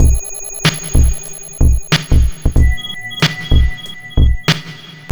BEAT 12 940B.wav